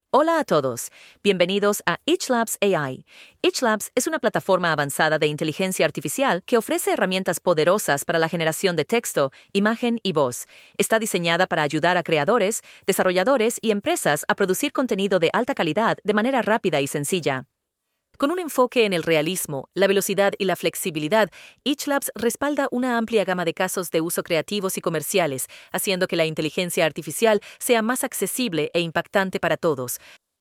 Translate and dub content into 20+ languages with natural, brand-consistent voices—preserving emotion, tone, and timing for professional localization.
elevenlabs-dubbing-output.mp3